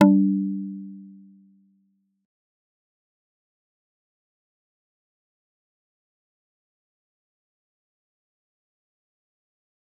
G_Kalimba-G3-mf.wav